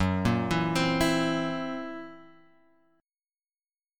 F#7b5 chord {2 1 2 x 1 0} chord